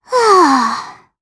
Pansirone-Vox_Sigh_jp.wav